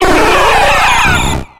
Cri de Mewtwo dans Pokémon X et Y.